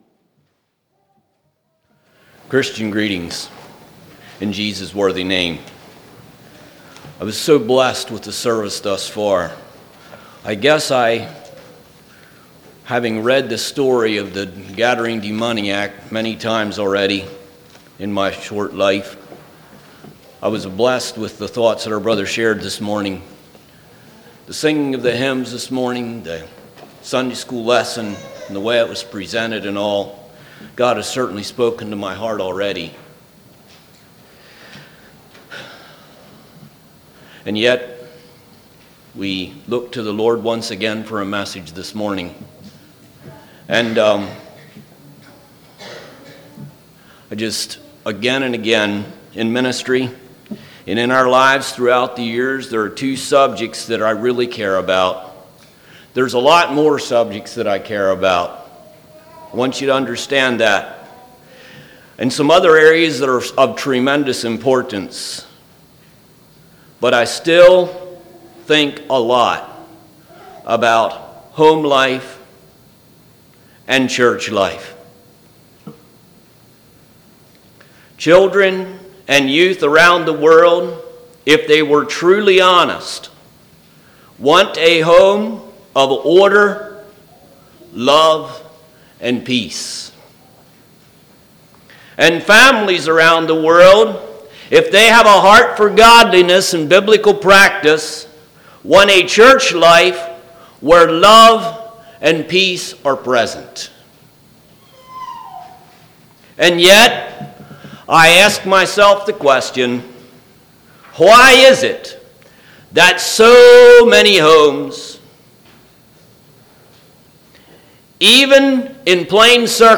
2018 Sermon ID